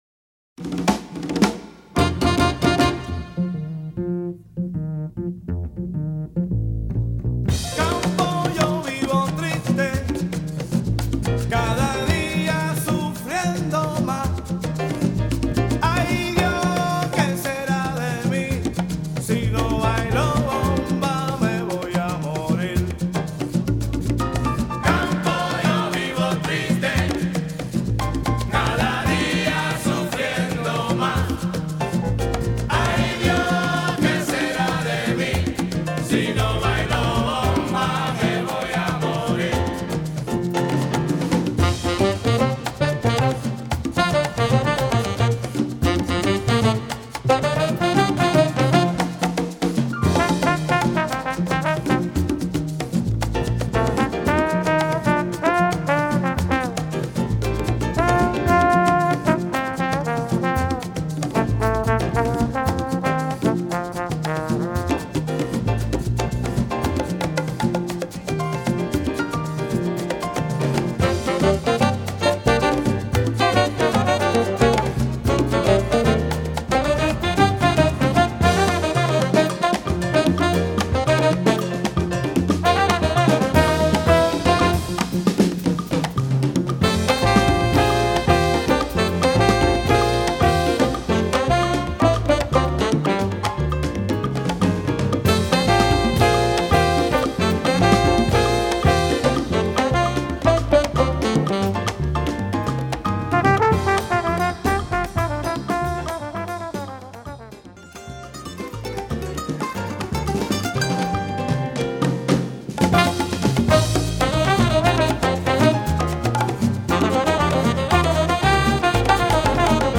Category: little big band
Style: bomba (sica)
Solos: open